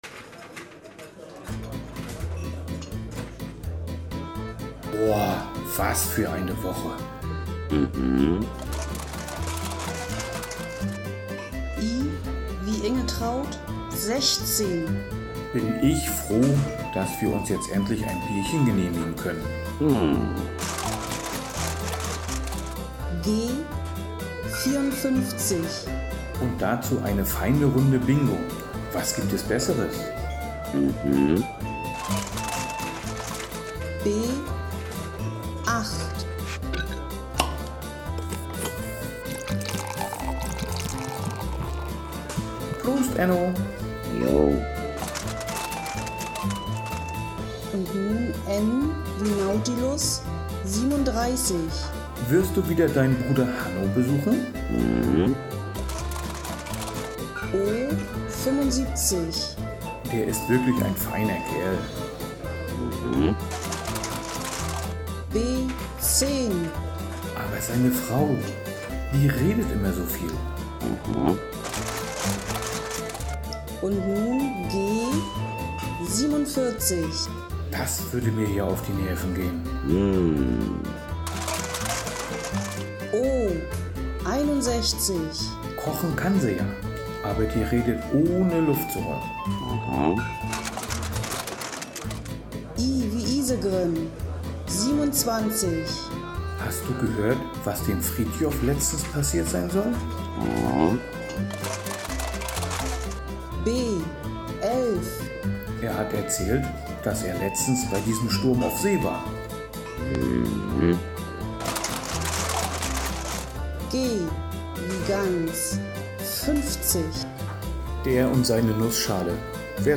Nante und Enno hatten eine anstrengende Woche als Fischer auf See und gönnen sich zum Abschluss ein wenig Klönschnack und das eine oder andere kühle Bierchen in einer alten Hafenkneipe.
Music by Nesrality from Pixabay